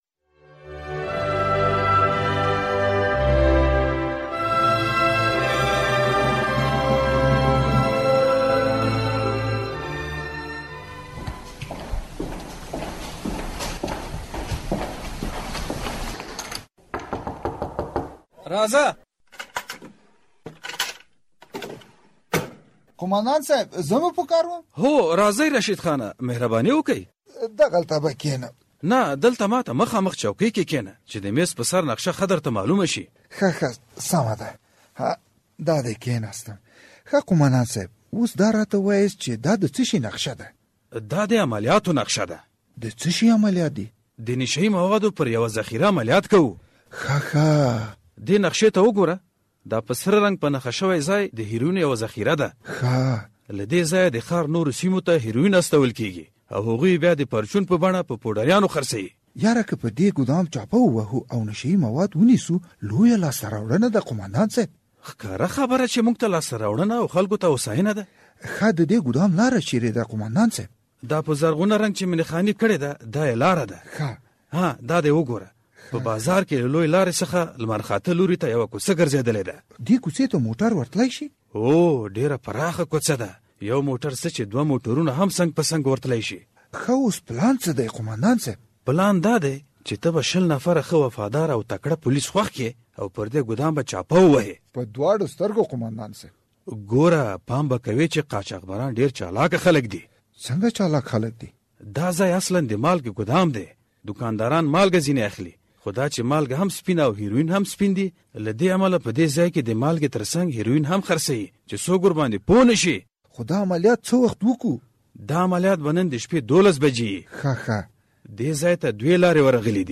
ډرامه